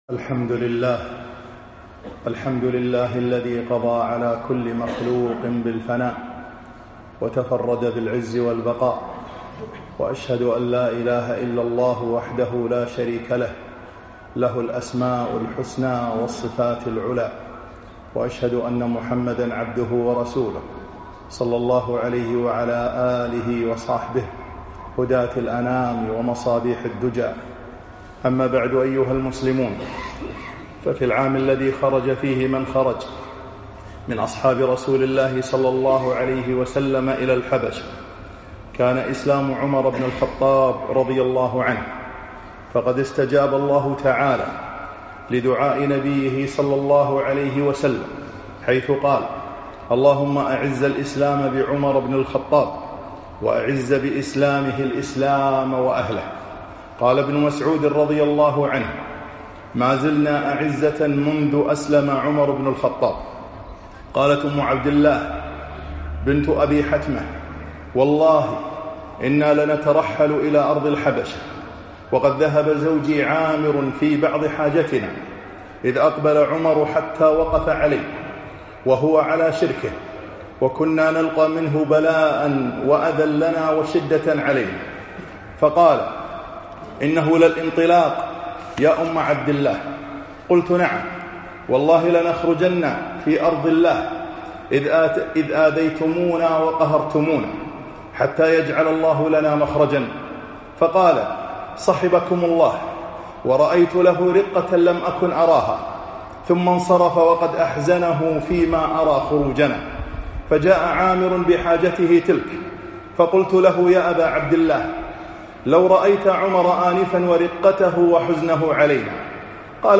خطب السيرة النبوية7